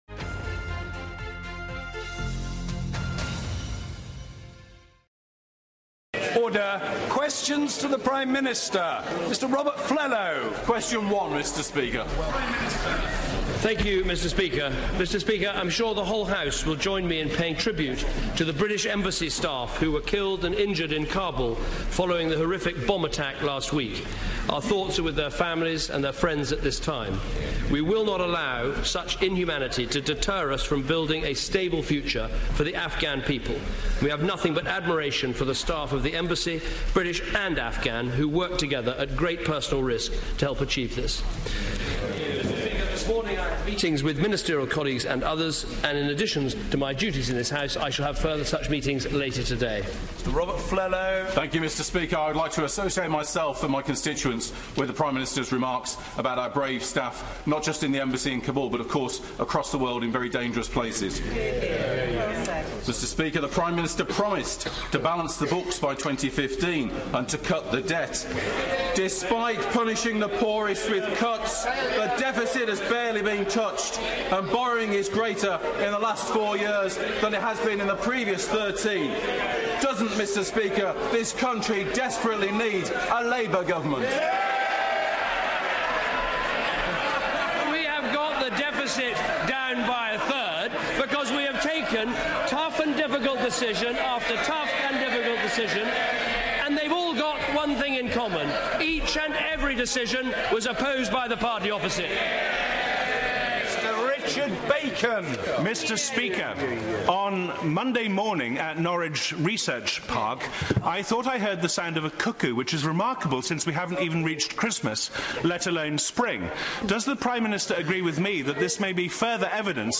PMQs: Cameron answers questions prior to the Autumn Statement